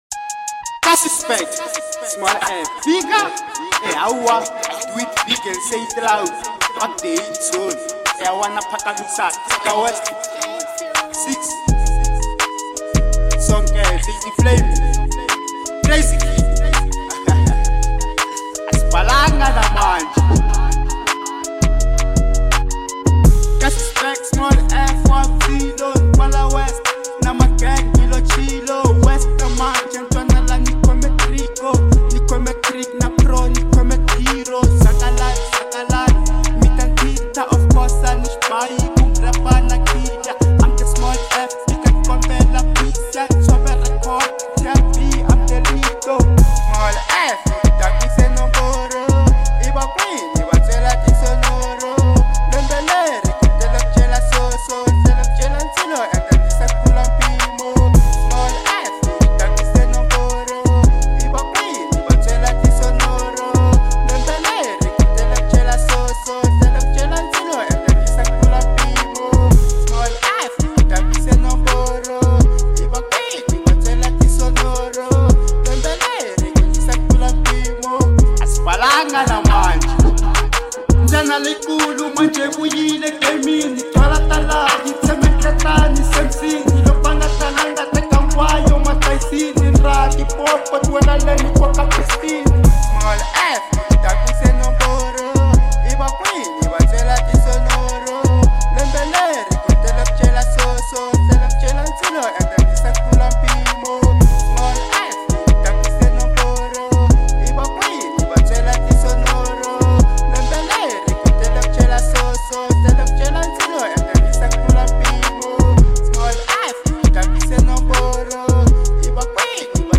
02:47 Genre : Hip Hop Size